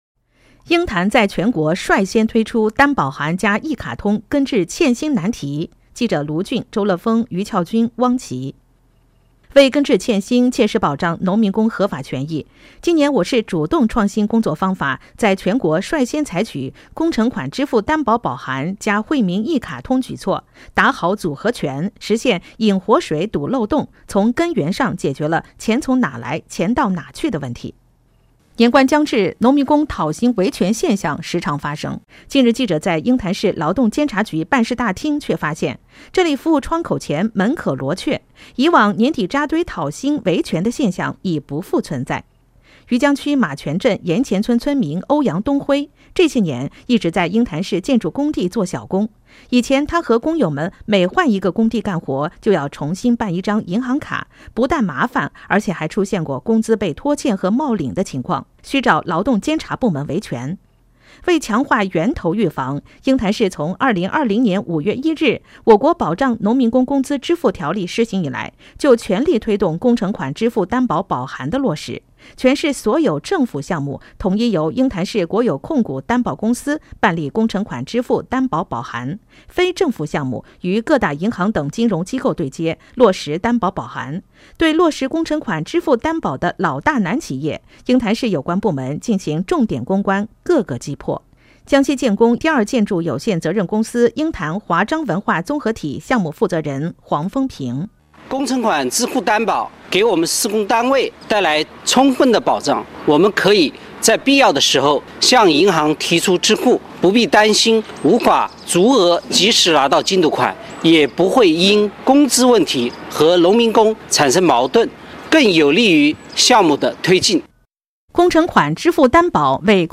附件3：广播消息《鹰潭在全国率先推出“担保函”+“一卡通”根治欠薪难题》音频